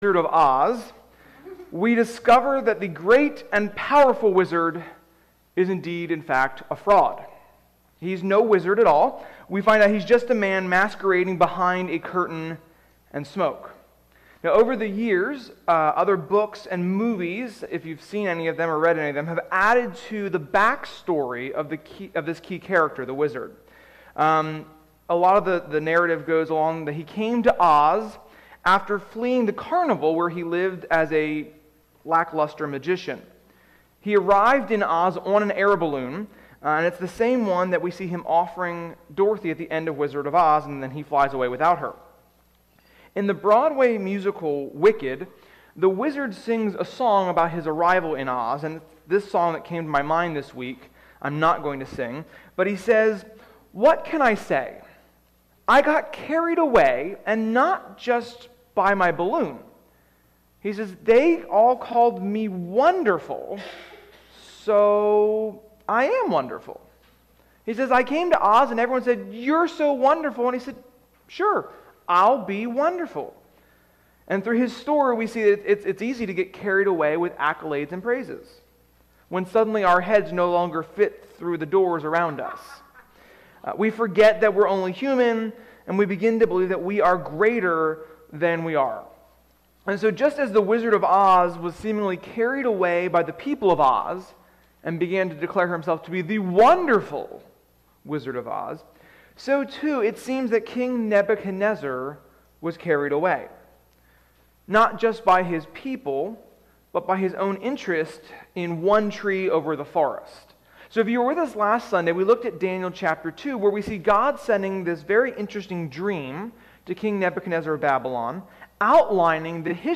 Sermon-6.20.21.mp3